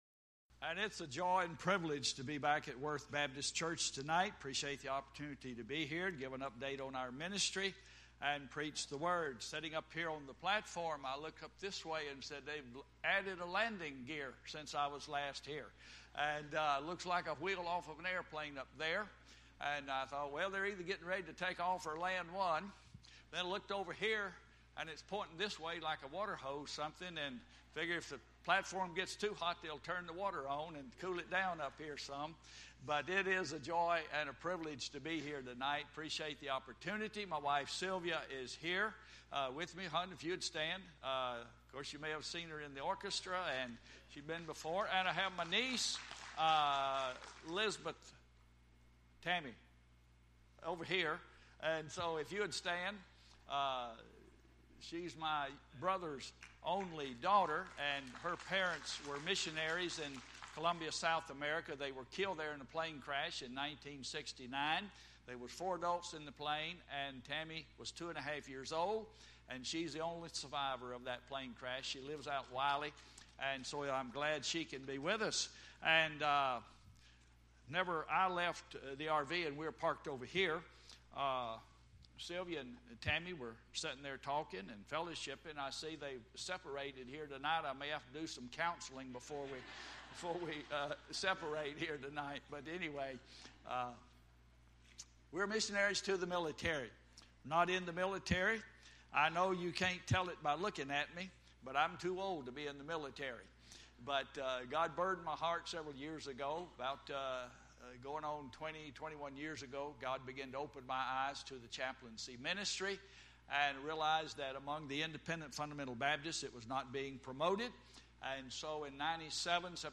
Media of Worth Baptist Church of Fort Worth, Texas
Sermons